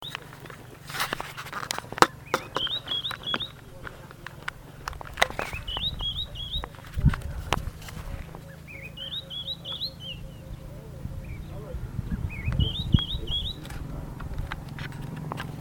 Cosifa de Heuglin (Cossypha heuglini)
Nombre en inglés: White-browed Robin-Chat
Condición: Silvestre
Certeza: Fotografiada, Vocalización Grabada